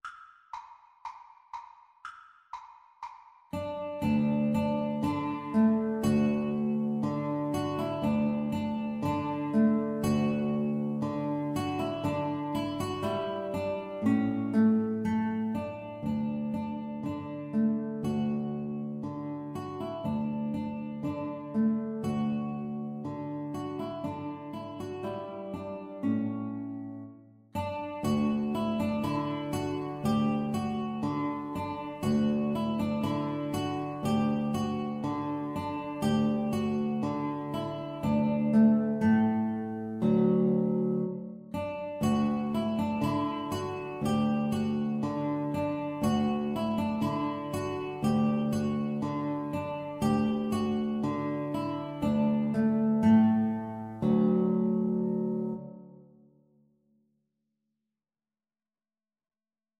D major (Sounding Pitch) (View more D major Music for Guitar Trio )
Allegro (View more music marked Allegro)
Classical (View more Classical Guitar Trio Music)